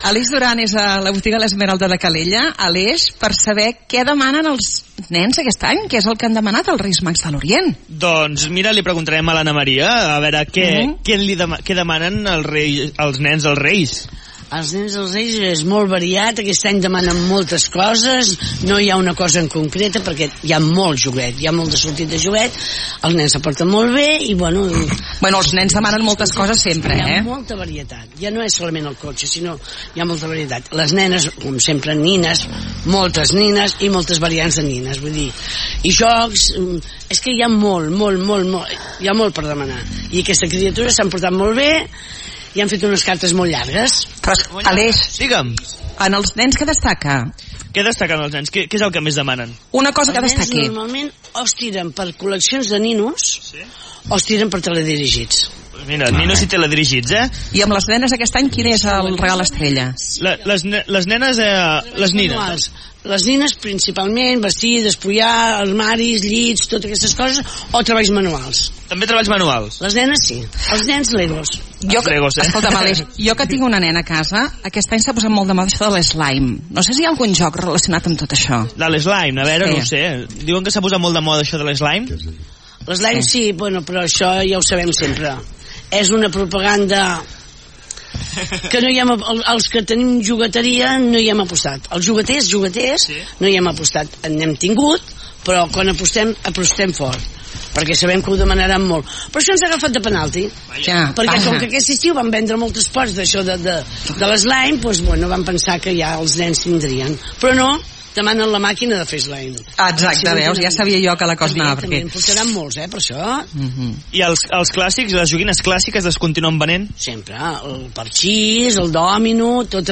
Hem anat a la botiga de joguines l'"Esmeralda" per saber quines són les preferències dels nens i nenes de Calella a l'hora de fer la seva carta als Reis.